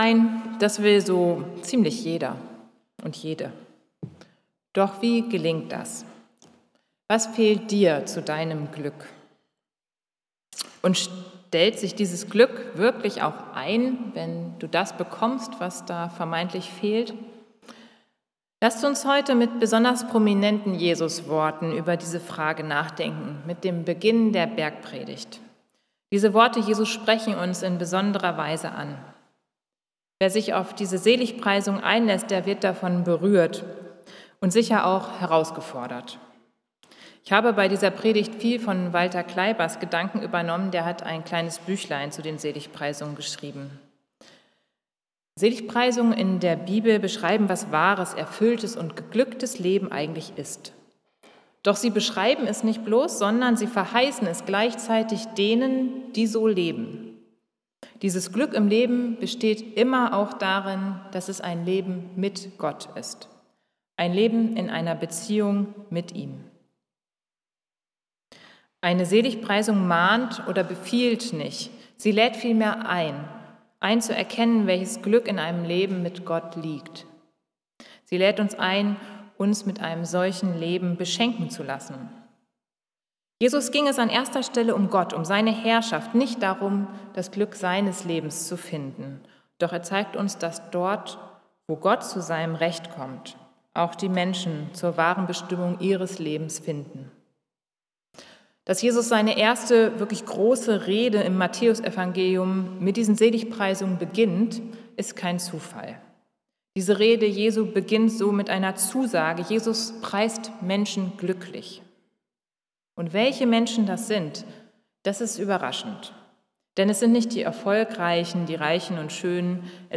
Predigt zu Matthäus 5, 1-5 - die Seligpreisungen | Bethel-Gemeinde Berlin Friedrichshain